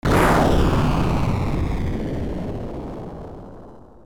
描述：砰砰砰
Tag: 140 bpm Techno Loops Fx Loops 693.10 KB wav Key : Unknown